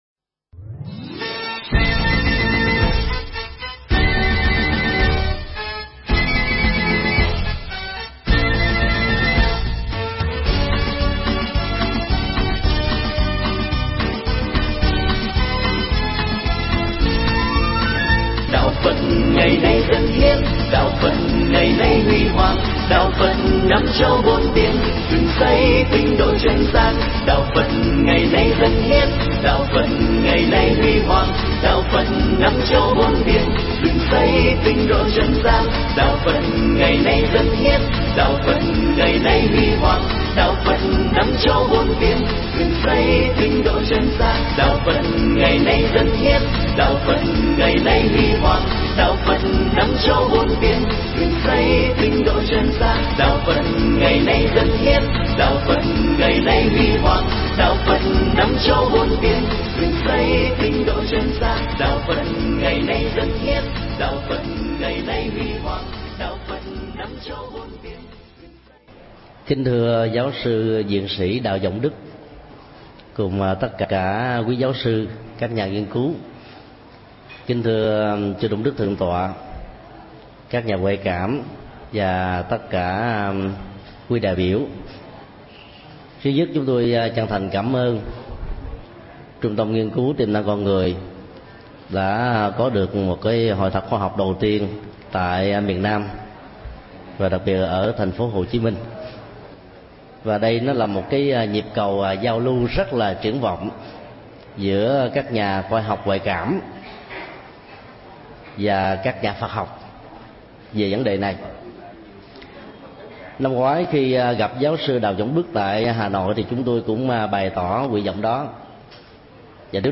Pháp thoại
giảng tại Hội thảo khoa học nguyên cứu về tiềm năng con người tại viện bảo tàng quân khu 7